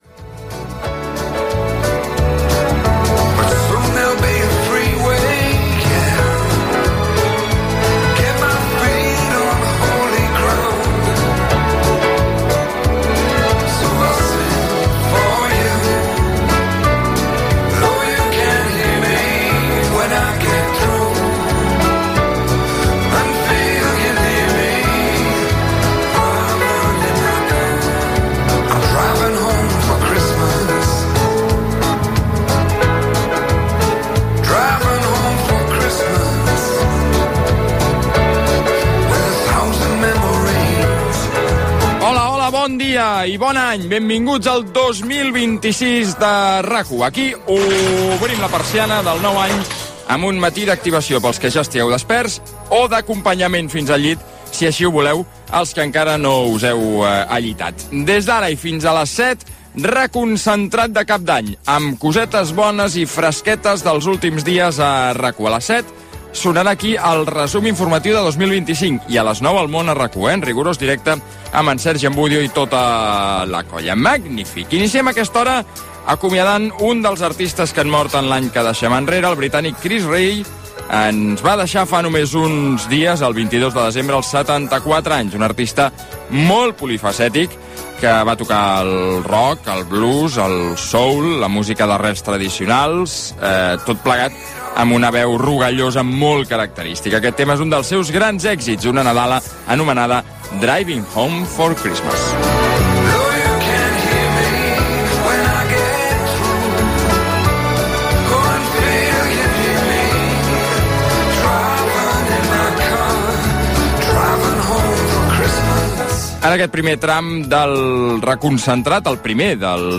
Desig de bon any 2026, hora, espais de RAC 1 d'aquell matí i record al músic Chris Rea mort feia pocs dies, recuperació de la secció de cinema del programa "La primera pedra",dedicada a la pel·lícula "Donetes" Gènere radiofònic Entreteniment